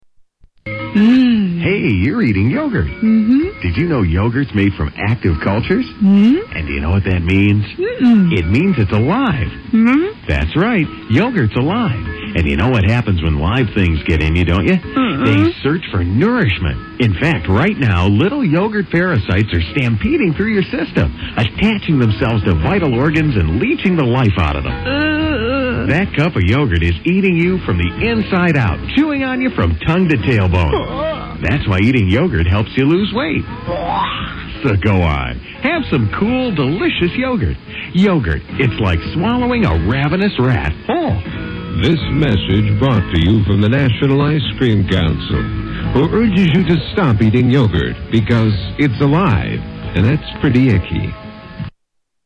Tags: Comedy Commercials Funny Commercials Commercials Comedy Funny